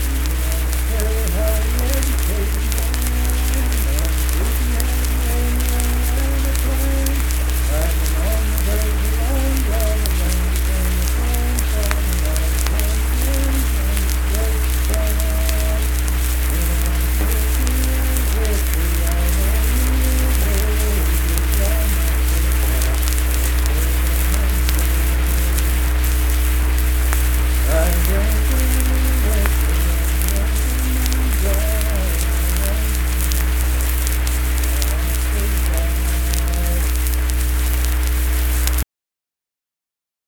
Unaccompanied vocal music
Performed in Kanawha Head, Upshur County, WV.
Voice (sung)